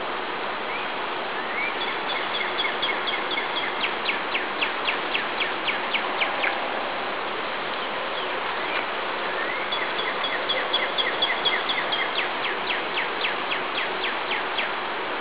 Northern Cardinal
Mill Grove, Audubon, PA, 3/23/00, multi-toned "whoits" (119kb)